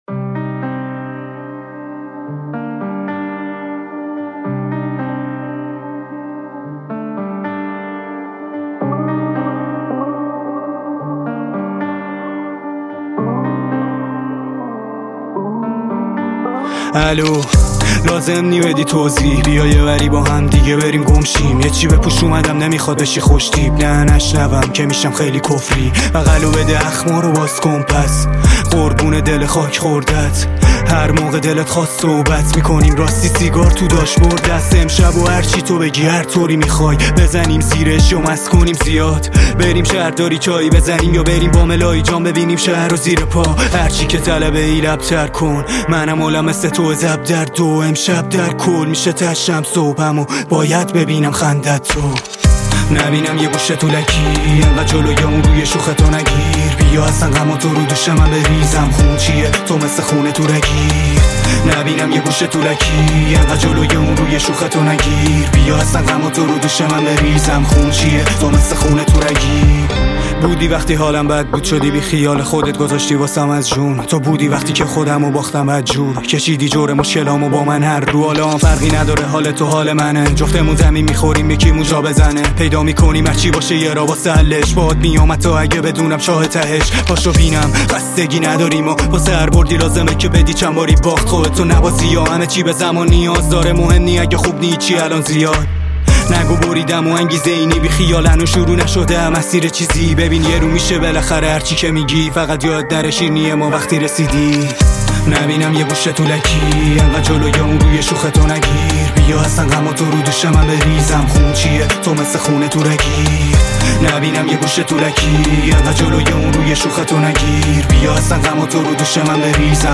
رپ
تک آهنگ